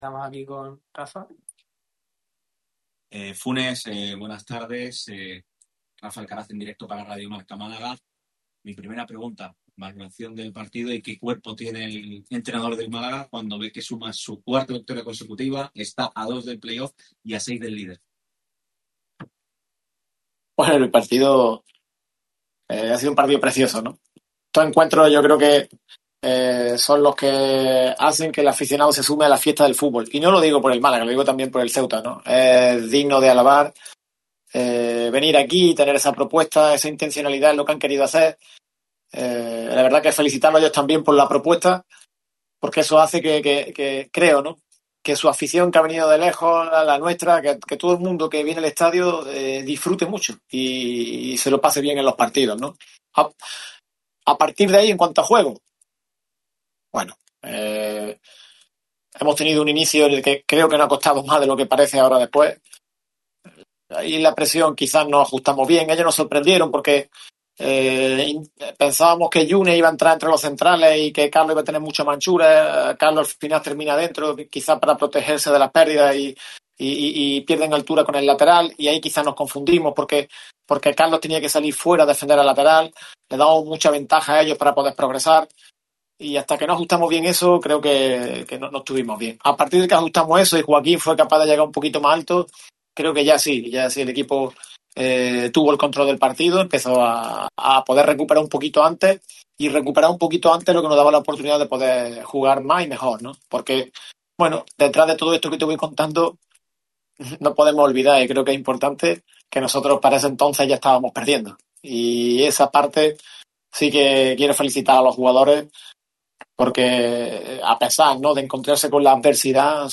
El entrenador del Málaga CF, Juanfran Funes, ha comparecido ante los medios en la sala de prensa de La Rosaleda tras la victoria por 2-1 ante la AD Ceuta. El lojeño ha valorado la victoria que deja a los blanquiazules a tan solo dos puntos de los puestos de playoff.